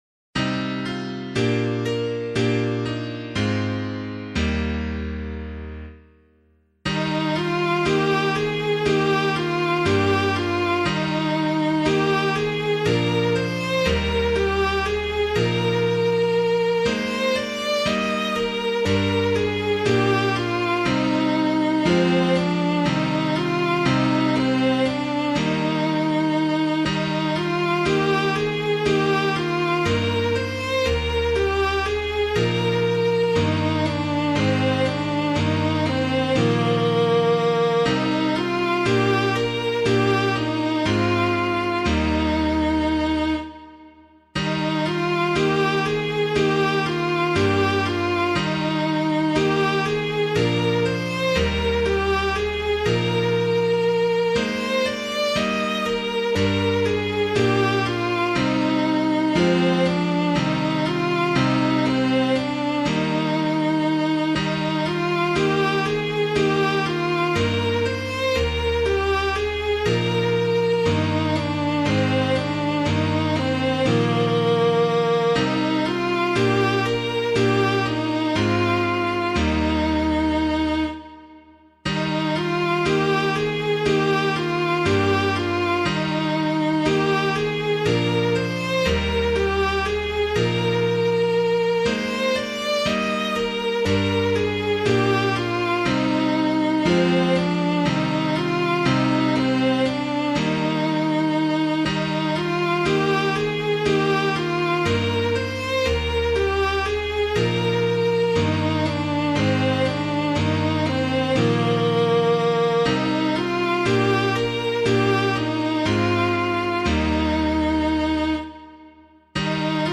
Hymn of the Day:  4th Sunday of Advent, Year A
Chant, Mode V
piano